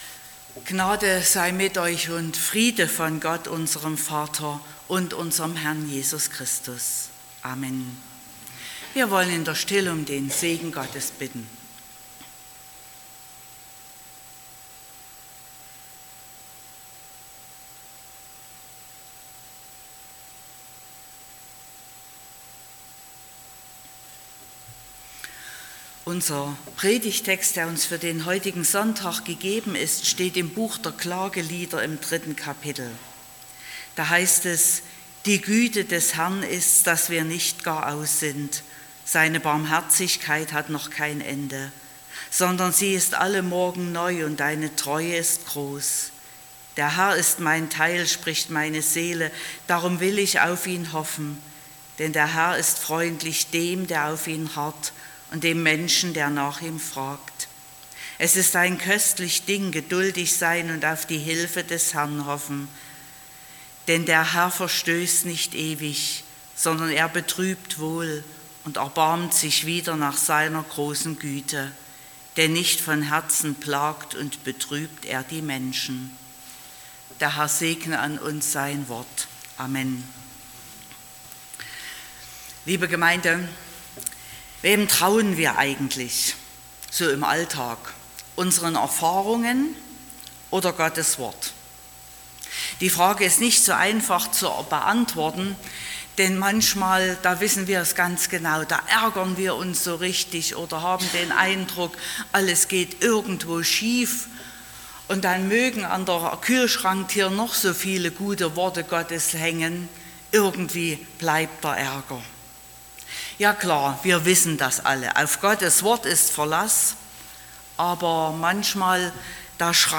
19.09.2021 – Gottesdienst
Predigt (Audio): 2021-09-19_Gott_fuehrt_in__bewahrt_vor_und_rettet_aus_Krisen.mp3 (23,4 MB)